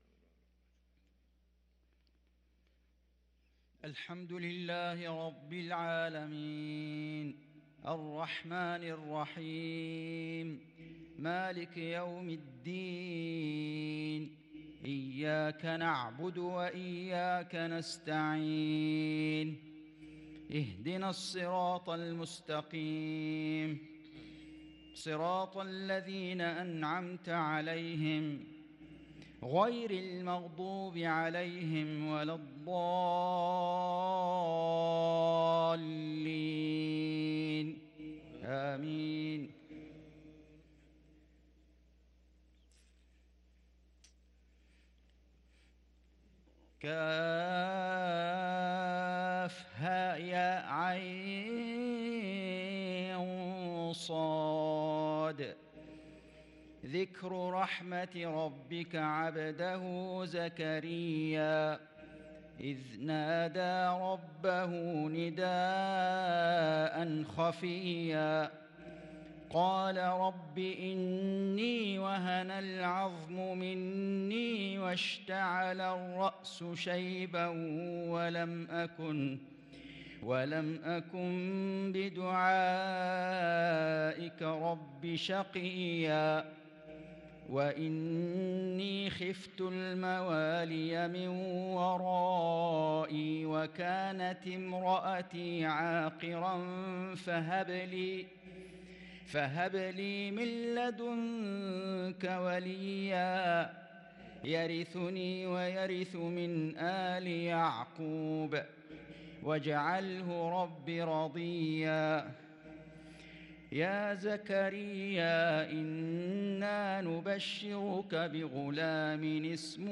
صلاة العشاء للقارئ فيصل غزاوي 17 جمادي الآخر 1443 هـ
تِلَاوَات الْحَرَمَيْن .